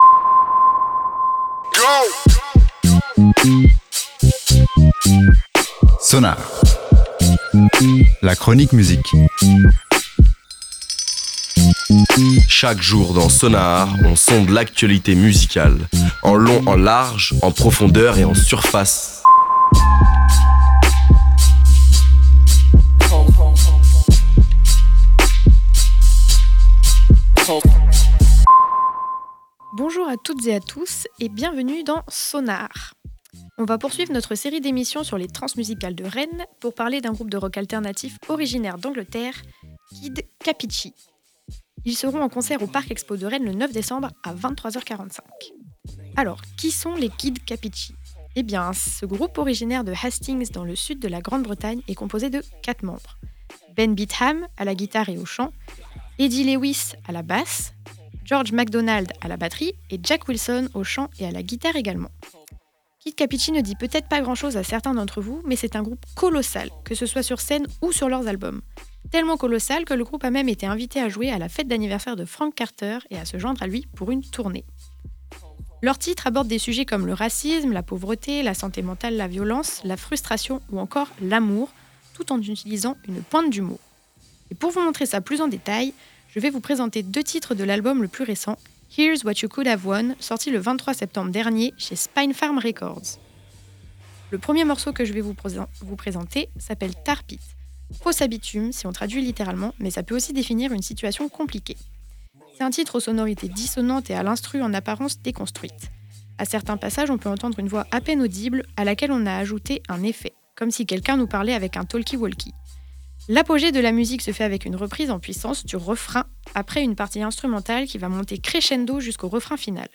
Réécoutez l'émission